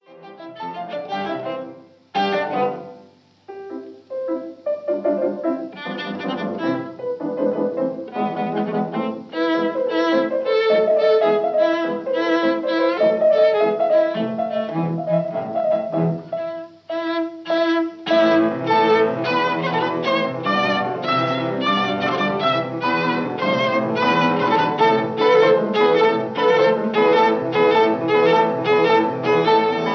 Scherzo (Allegro molto)
piano